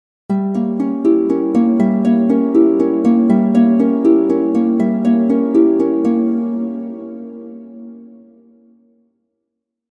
Magical Transitional Harp Sound Effect
A smooth, magical harp melody perfect for fantasy, holiday scenes, and cinematic transitions. It creates an enchanting atmosphere for videos, games, or multimedia projects.
Magical-transitional-harp-sound-effect.mp3